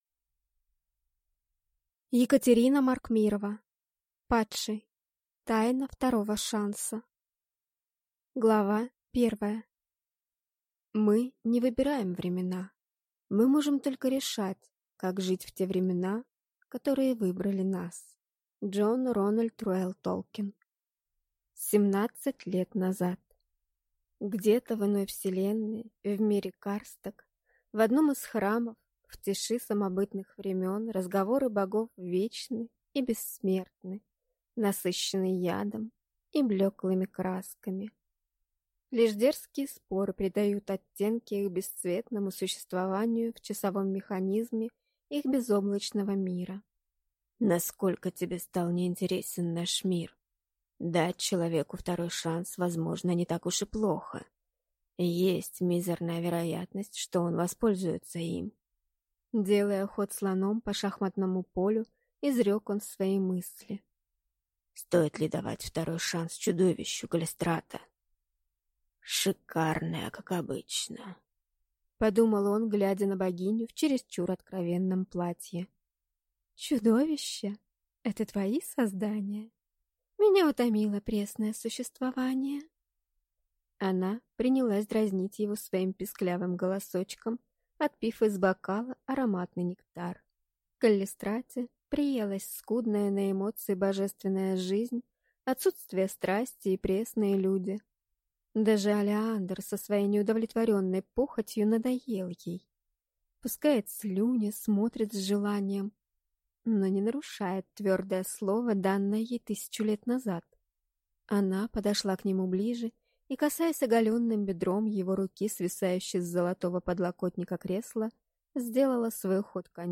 Аудиокнига Падший. Тайна второго шанса | Библиотека аудиокниг